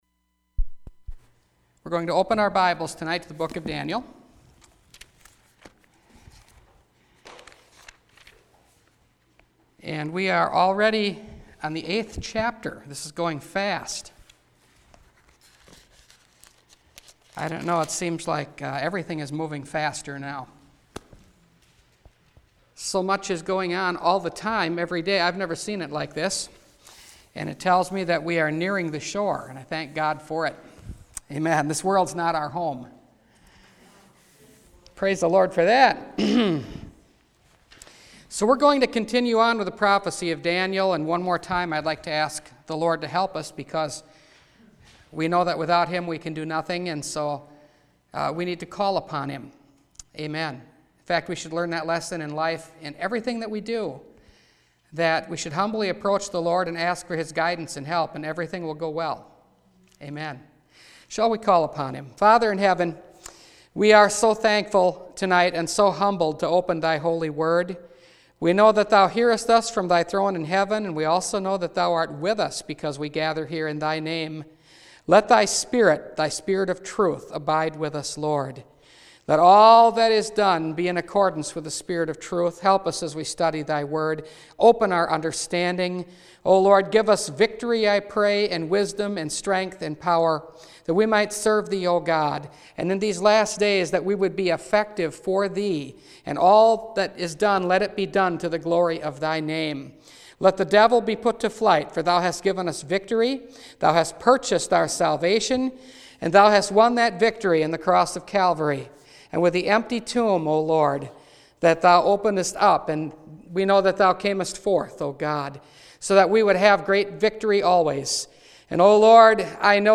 Daniel Series – Part 8 – Last Trumpet Ministries – Truth Tabernacle – Sermon Library